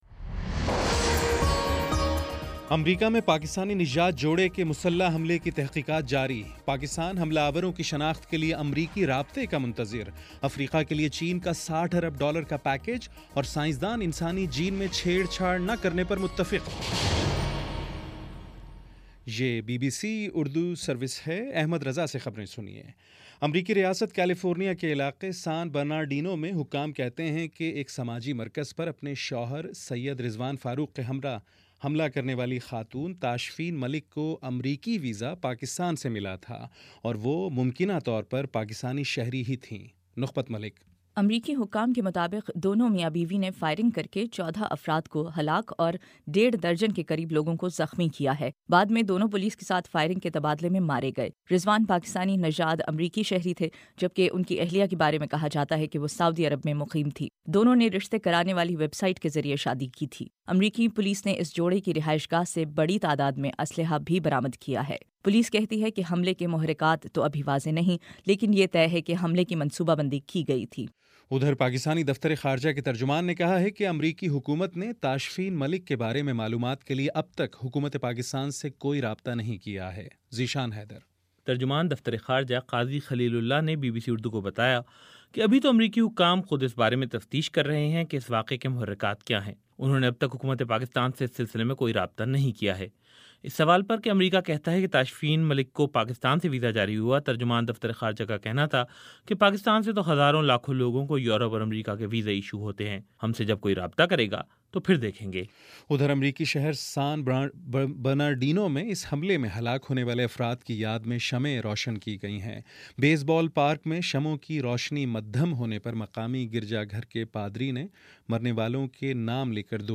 دسمبر 04 : شام پانچ بجے کا نیوز بُلیٹن